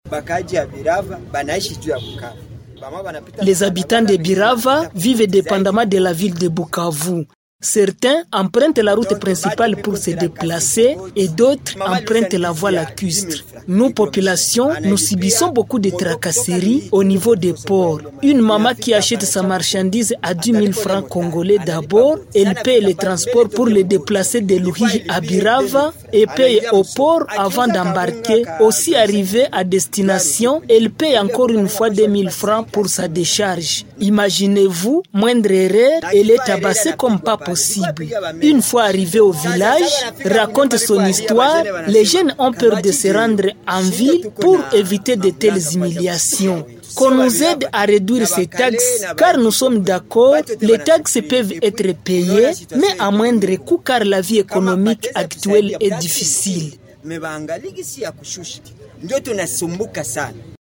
Ils l’ont exprimé au gouverneur de province sous AFC/M23 lors de son passage le dimanche 25 Mai 2025 au centre de négoce de Birava dans le territoire de Kabare.
L’un d’eux s’exprime: